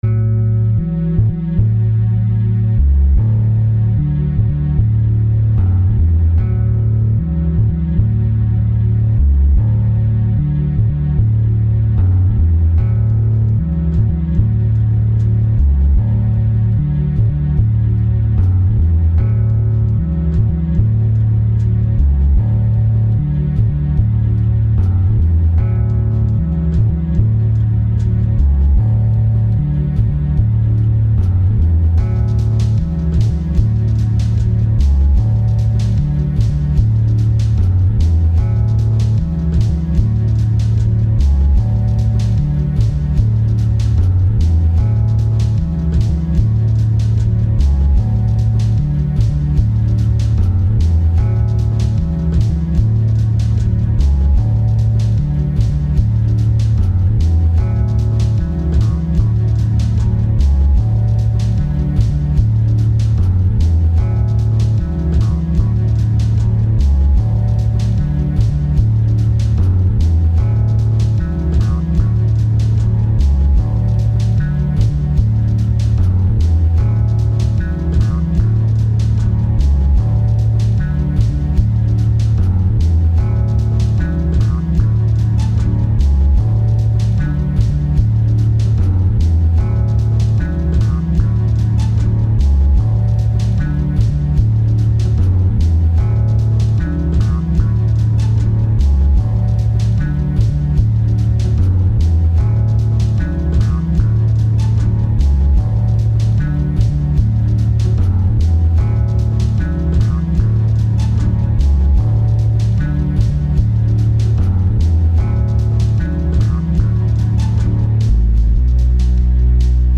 Vocals are still poor but a bit more directed.